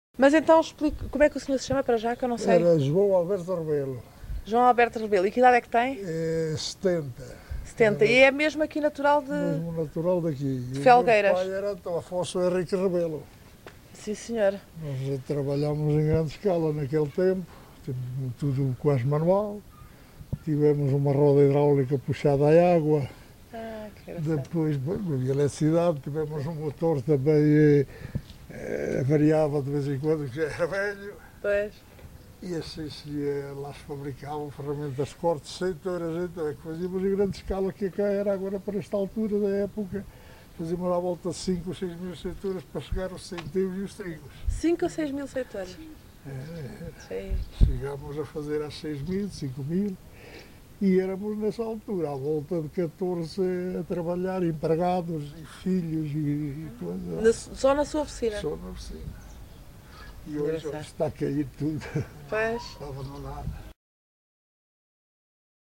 LocalidadeLarinho (Torre de Moncorvo, Bragança)